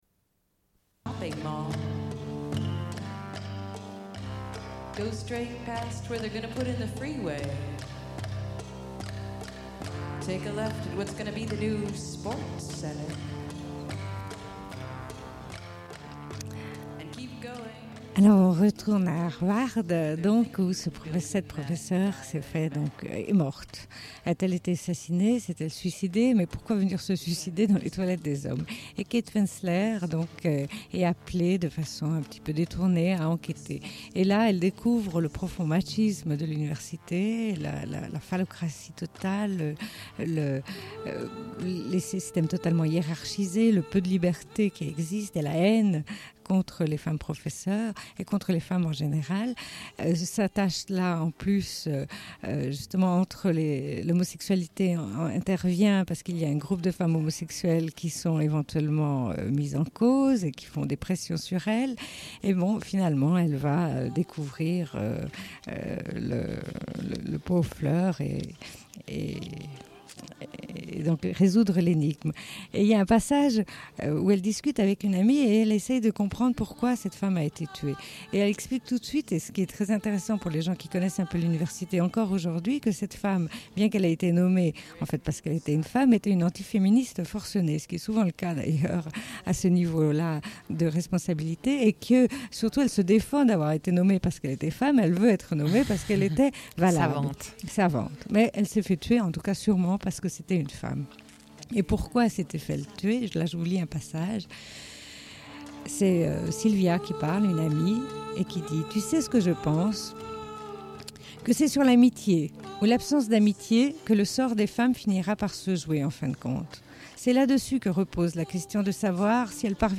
Suite de l'émission : au sujet du film Mossane avec la réalisatrice sénégalaise Safi Faye. Diffusion d'un entretien.